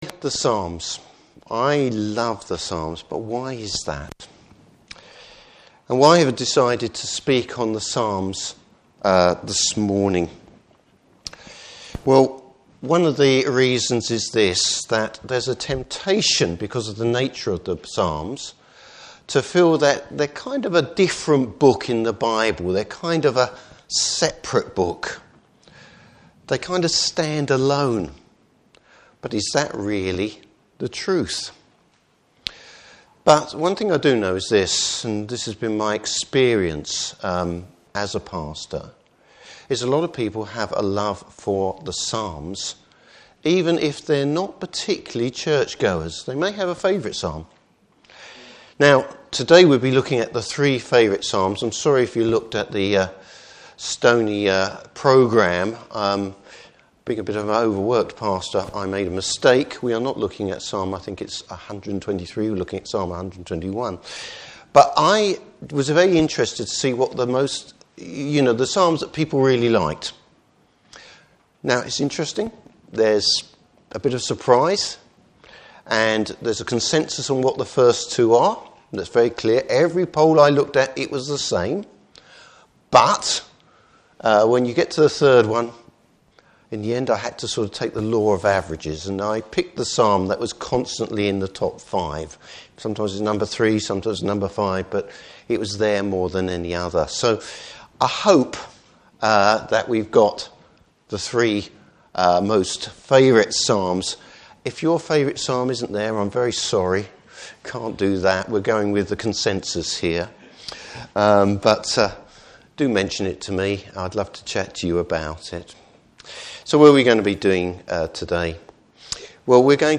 Service Type: Stony Words Commuity Event.